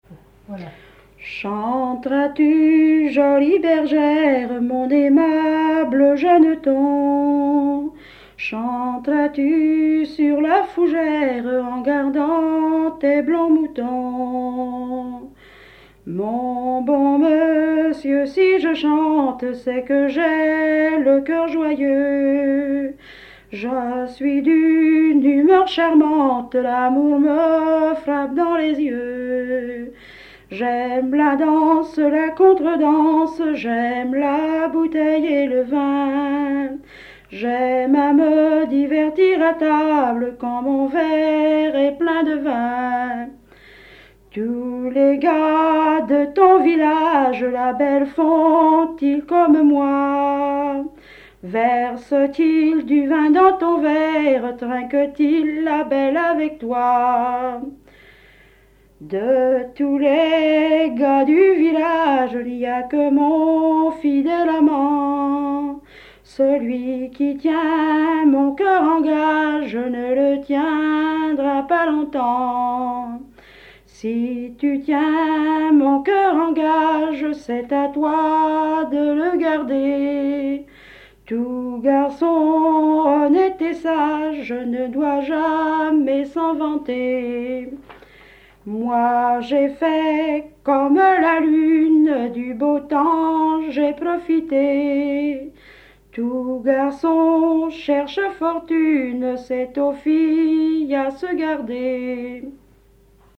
Localisation Barbâtre
Genre strophique
Chansons traditionnelles